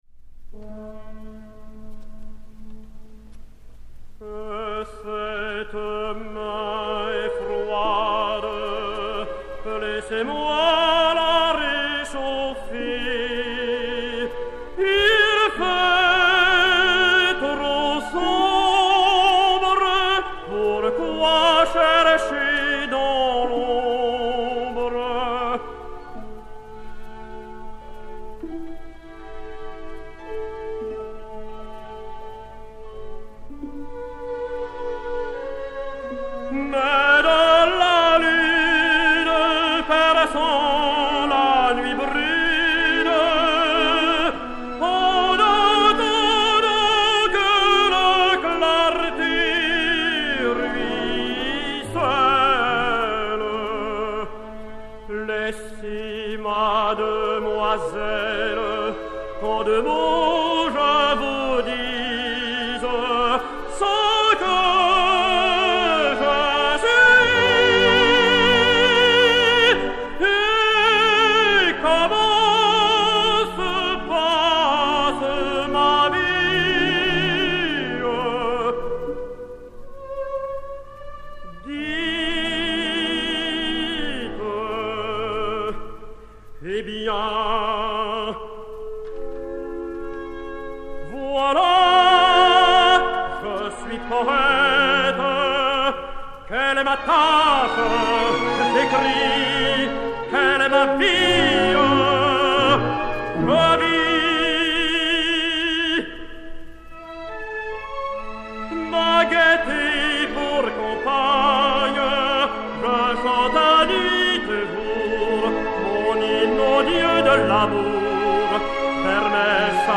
ténor français
et Orchestre Symphonique dir.